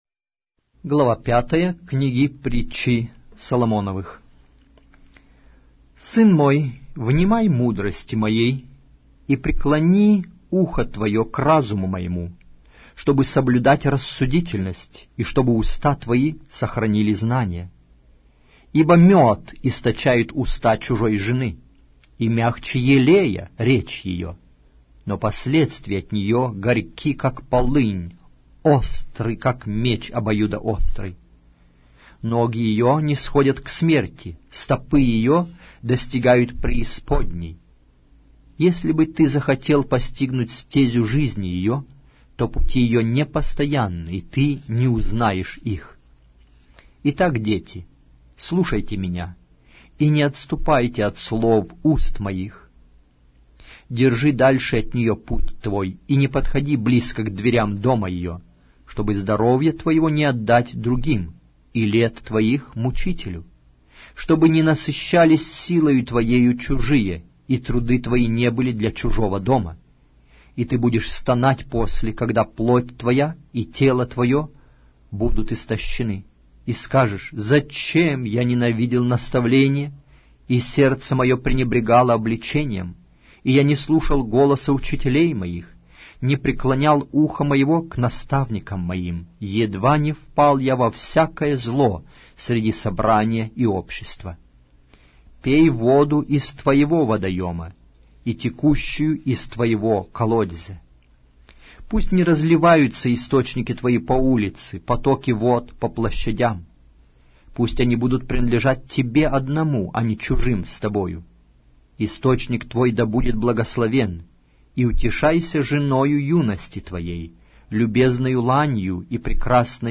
Аудиокнига: Притчи Соломона